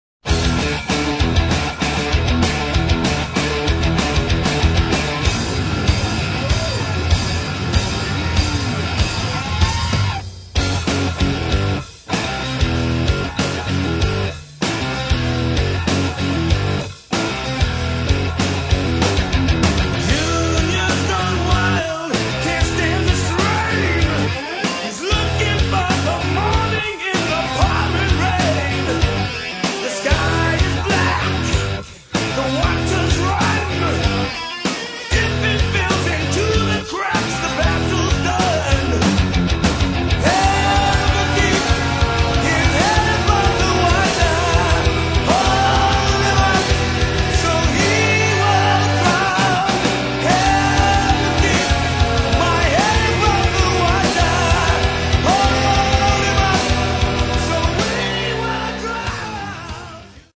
Recorded at Rampart Studios, Houston
Recorded at Studio Marcadet, Paris